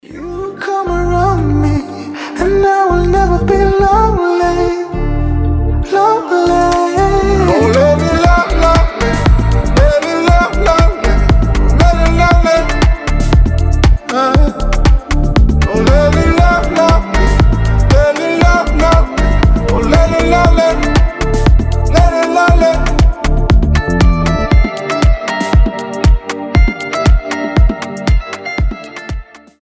• Качество: 320, Stereo
мужской голос
deep house
мелодичные